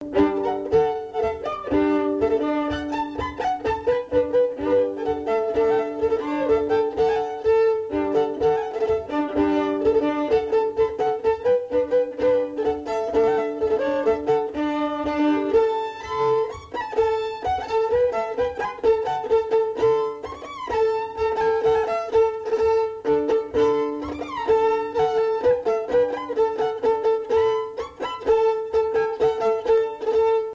Mémoires et Patrimoines vivants - RaddO est une base de données d'archives iconographiques et sonores.
danse : polka
Pièce musicale inédite